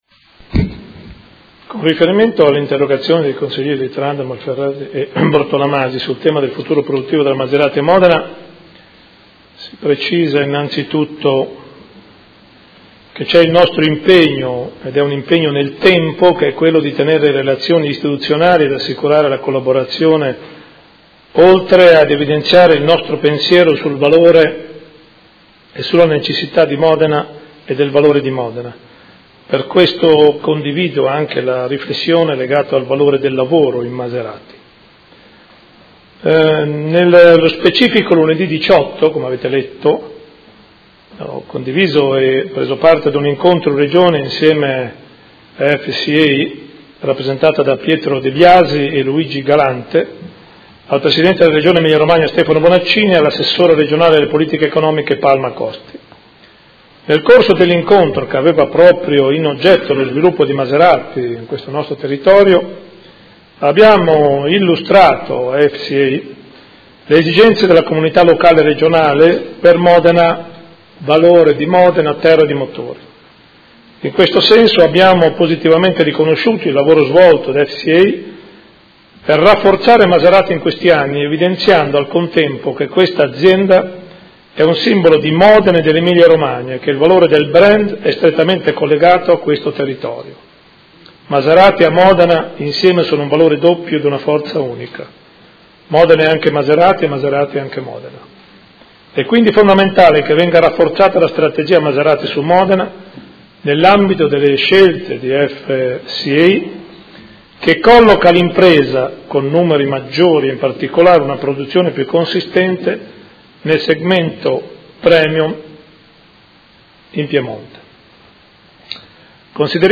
Seduta del 20/04/2016. Risponde a interrogazione dei Consiglieri Malferrari, Trande e Bortolamasi (P.D.) avente per oggetto: Si aggrava la crisi alla Maserati di Modena
Sindaco